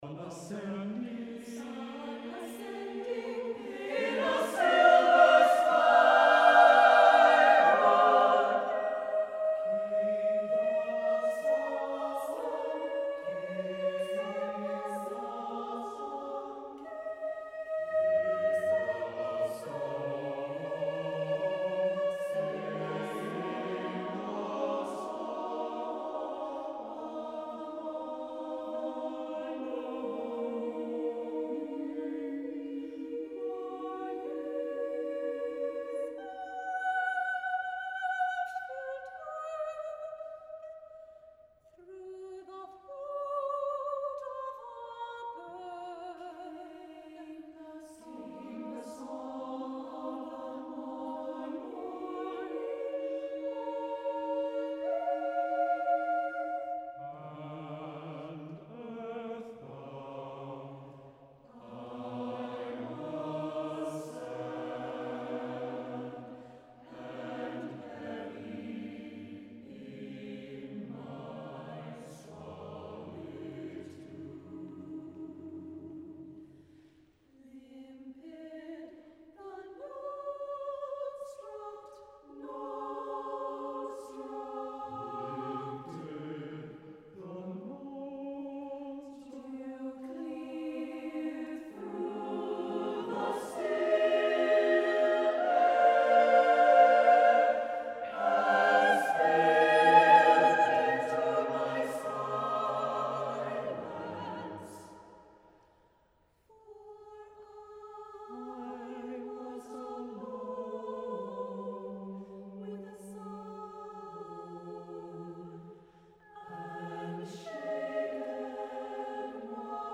SATB Choir with Soprano Solo (from within choir)
(SATB, S solo)